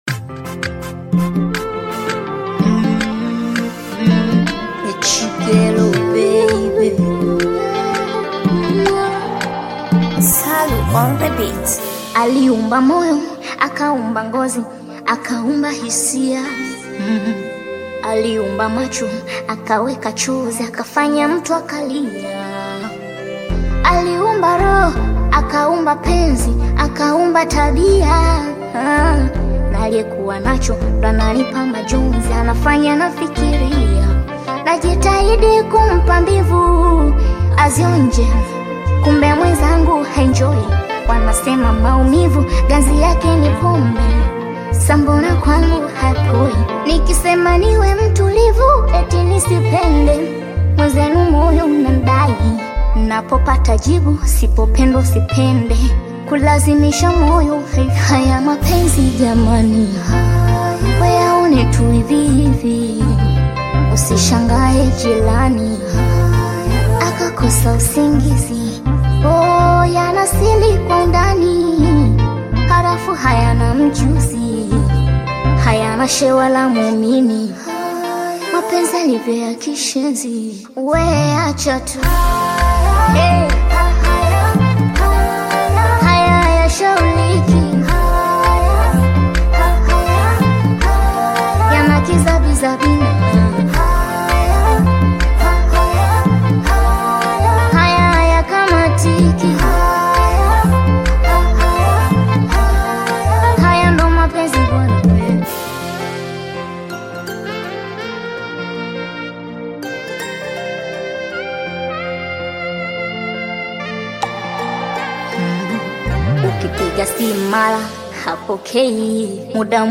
Bongo Flava
a love song